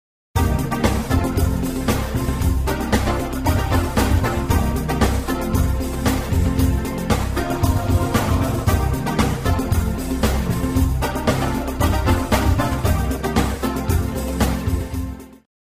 The following sound excerpts illustrate the gradual degradation of the repeatedly encoded/decoded audio:
Encoded/decoded, generation #8
As can be observed, the accumulated distortion quickly becomes audible (i.e. exceeds the masking threshold) and becomes more and more objectionable as the generation number increases.